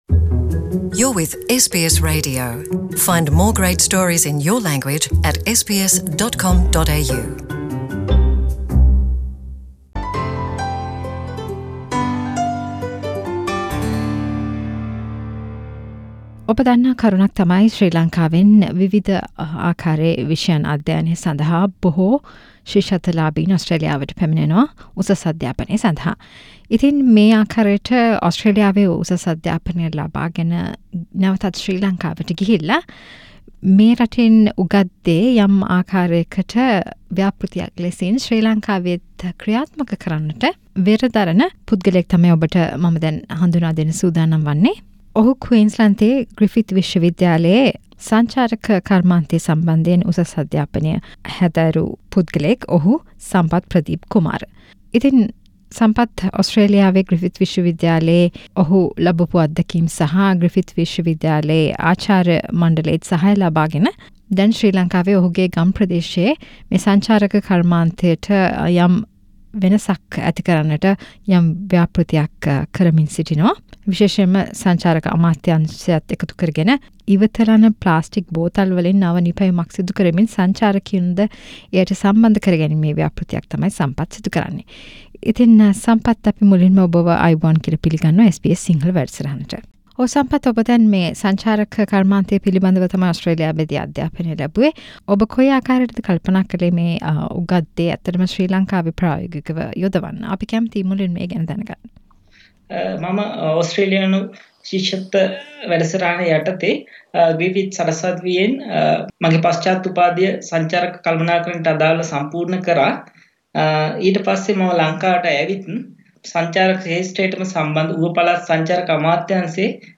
SBS සිංහල සිදු කල සාකච්චාවක්